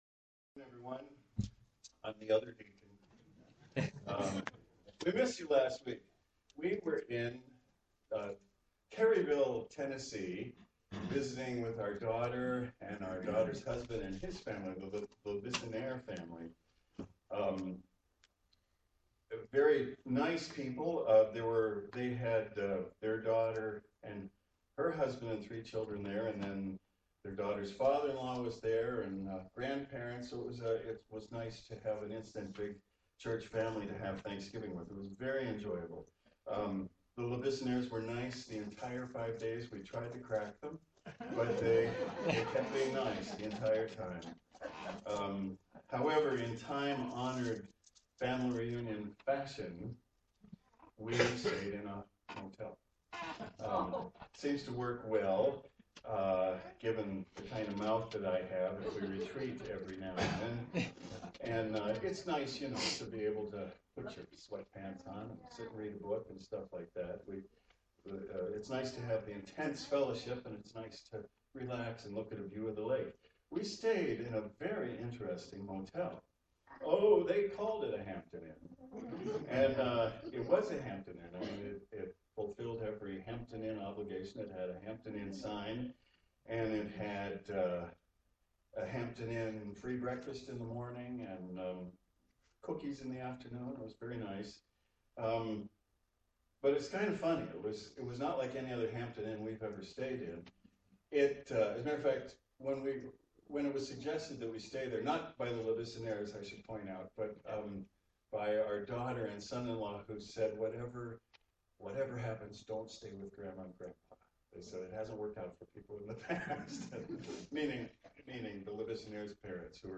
Given in Cleveland, OH
UCG Sermon Studying the bible?